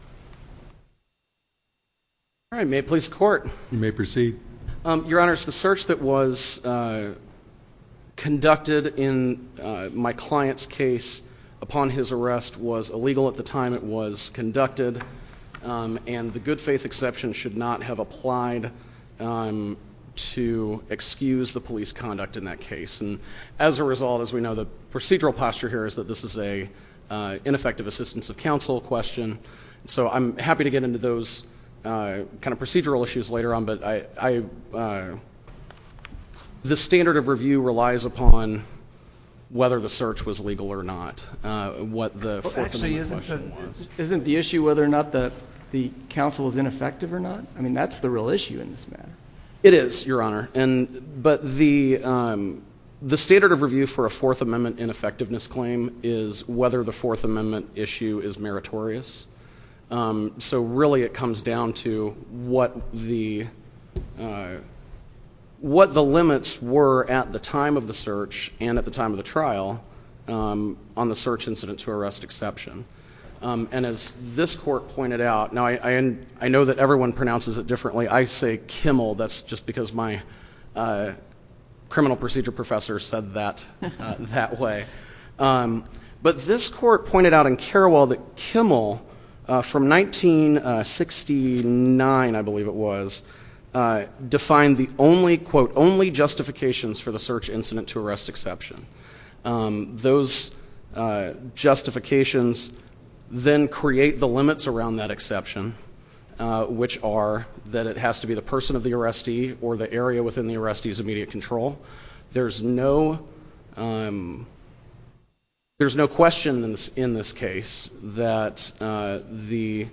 Constitutional challenge to search and seizure in drug possession case Listen to the oral argument